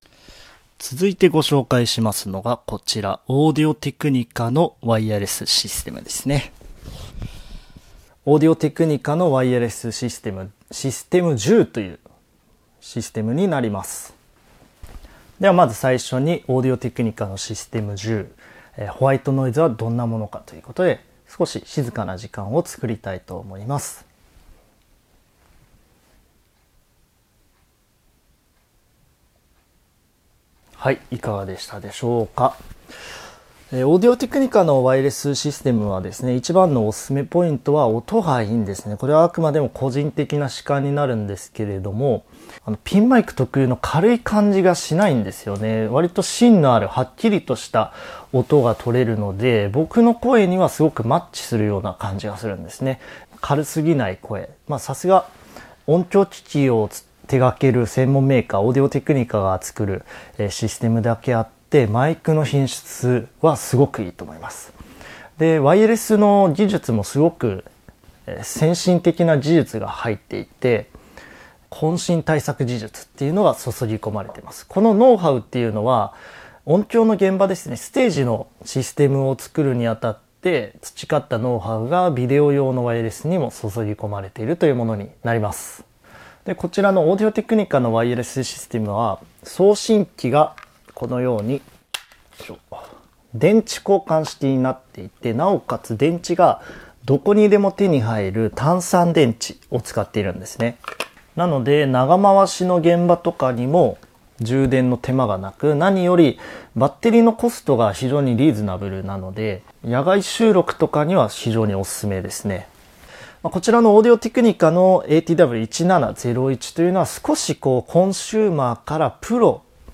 ホワイトノイズの少なさ：★★★
音質：★★★
ノイズも少ないですね。
小型&軽量のラベリアマイクロホンを組み合わせた2.4GHzデジタルワイヤレスシステム。
今回、サンプル音源では検証のためノイズリダクションを一切行っておりませんが、iZotopeのノイズ除去ソフトを使えば耳に付くノイズというのは随分と軽減できます。